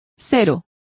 Complete with pronunciation of the translation of nil.